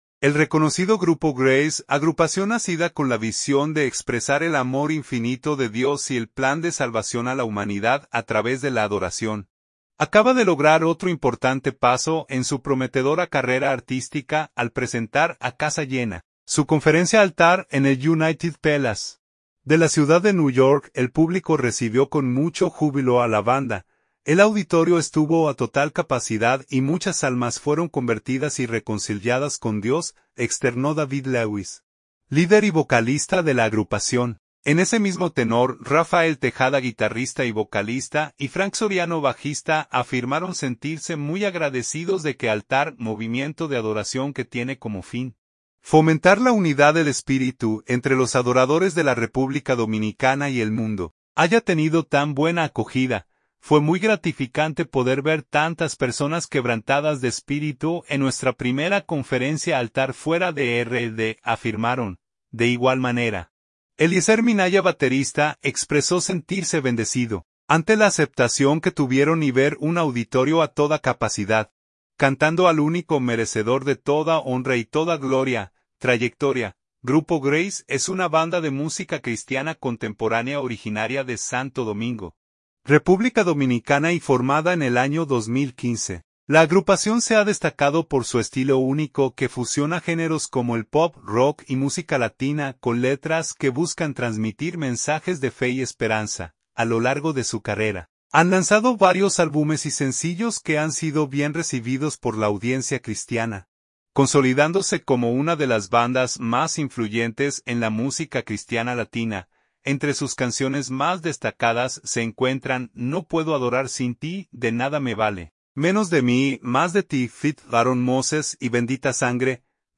banda de música cristiana contemporánea